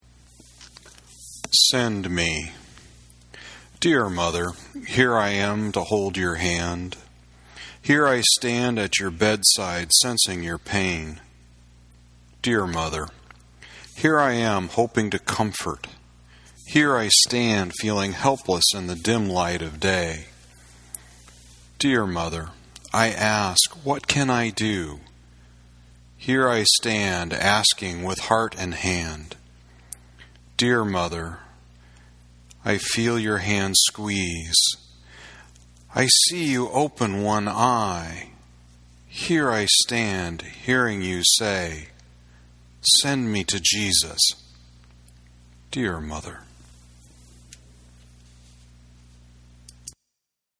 I was looking in my book, thinking I might put my headset on and record a reading of one of the poems.
The nature of the poem causes me to fall into that voice of a detached poet.
Your voice has the perfect cadence for reading poetry, which I guess isn’t very surprising.